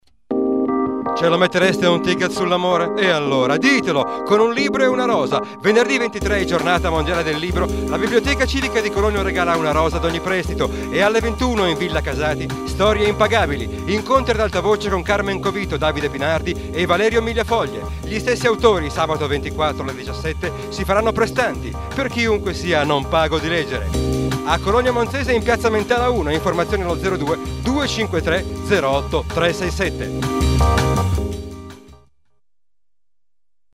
Spot su Radio Popolare (.mp3) iniziativa Cologno del 23 aprile